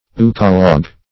Euchologue \Eu"cho*logue\, n.